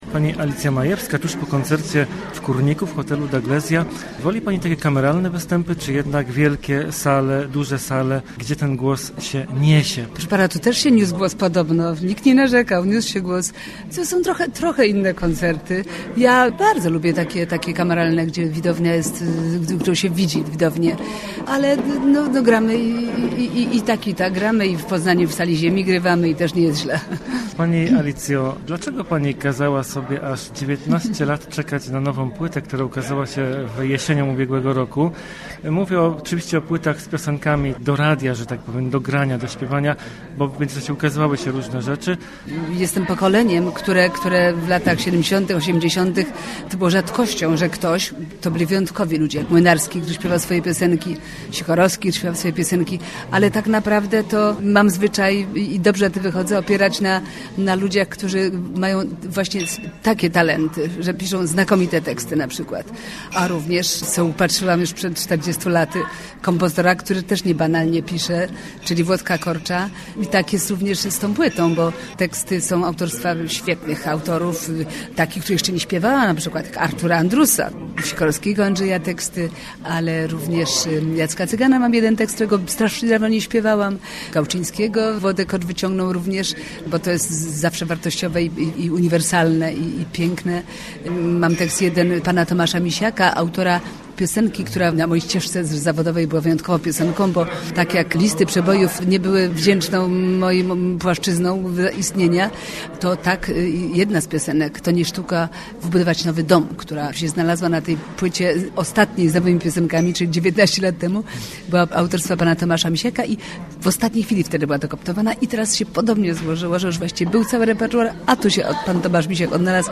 po jej koncercie w Kórniku.